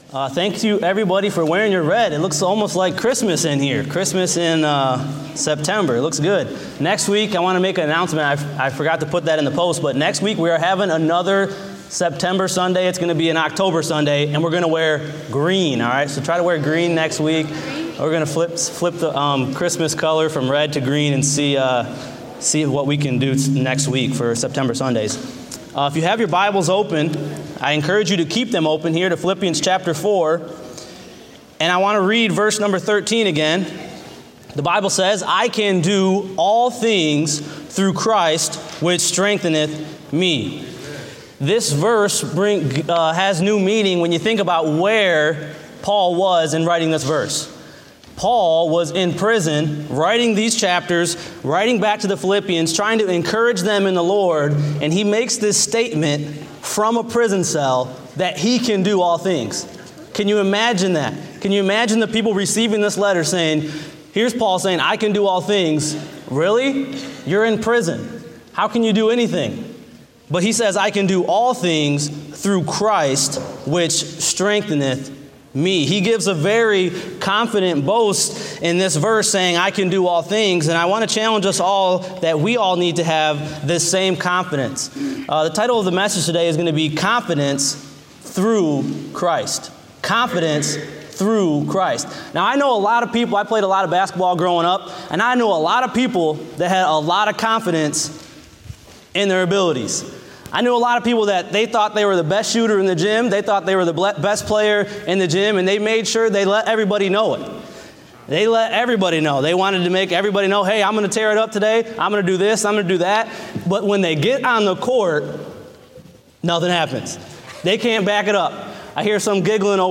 Date: September 25, 2016 (Morning Service)